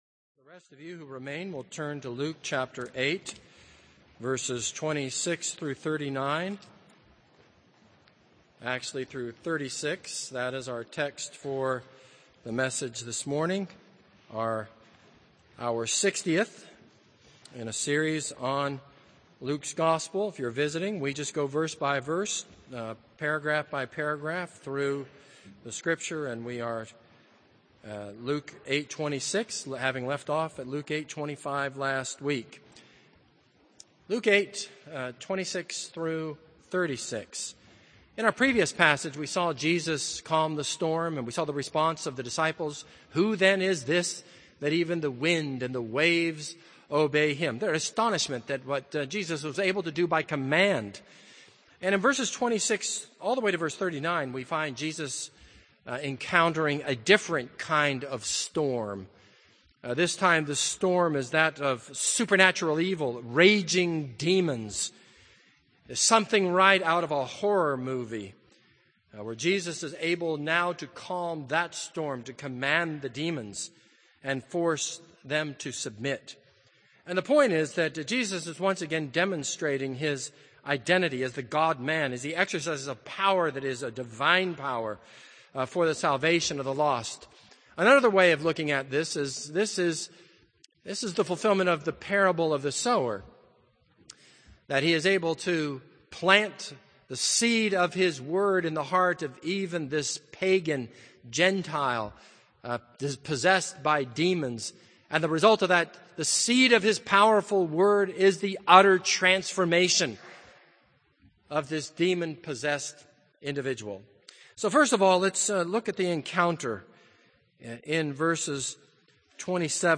This is a sermon on Luke 8:26-39.